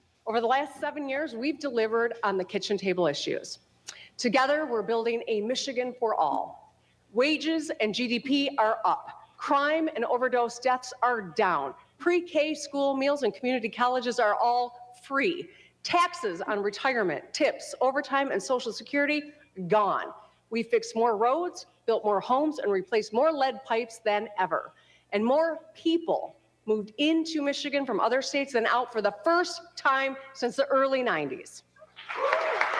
Addressing a joint session of the legislature, Whitmer proposed the “Every Child Read” plan, which she described as the largest targeted literacy investment in state history.